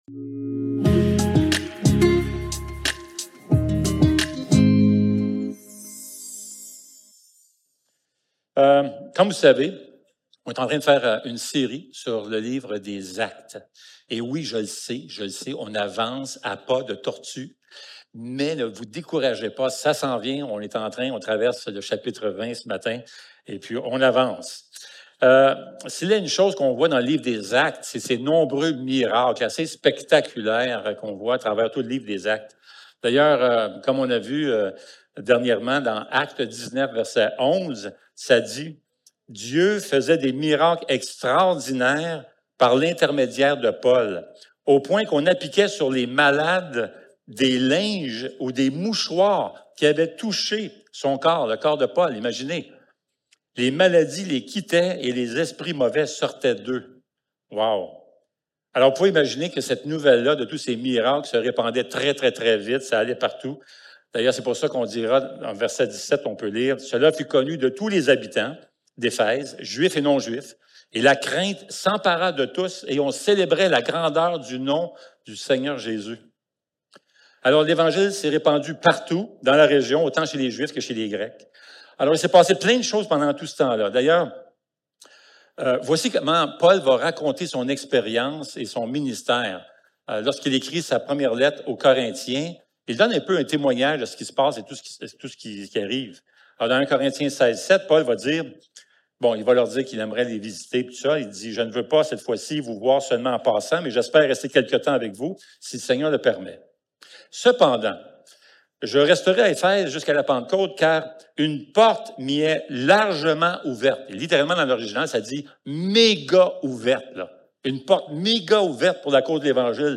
Actes 20 Service Type: Célébration dimanche matin Envoyés #32 Quoi faire lorsque notre quotidien nous semble si banal